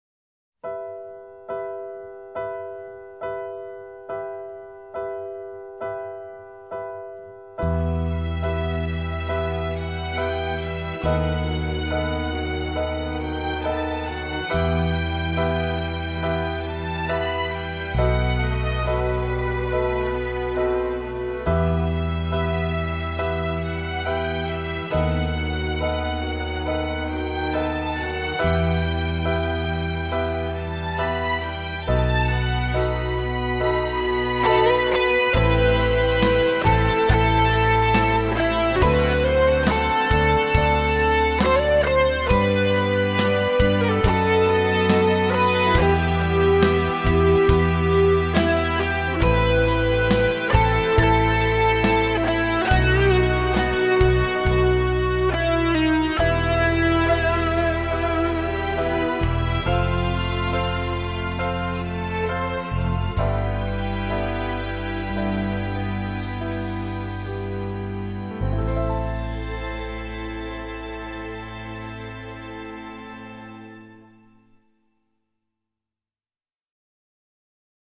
- Очень красивая, но в то же время печальная тема.
Трогательная, чувственная композиция;